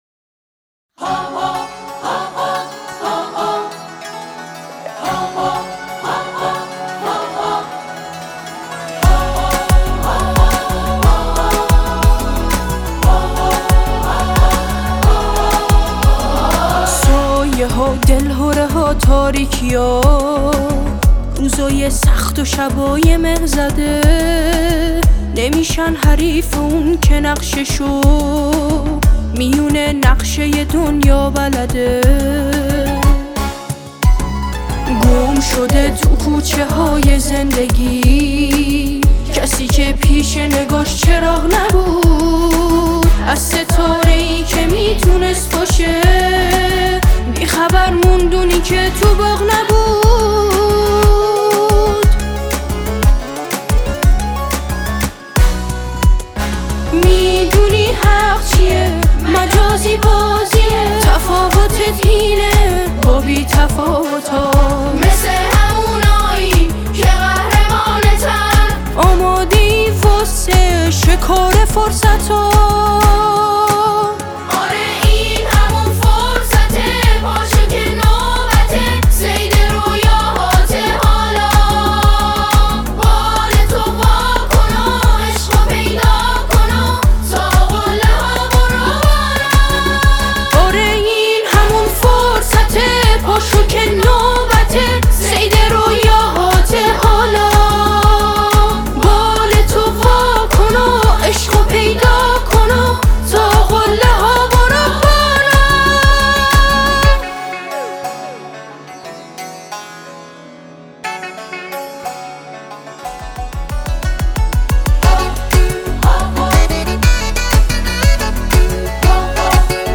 اثری شاد و انگیزشی
فضایی پرنشاط و امیدبخش